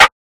Sn (OneDance).wav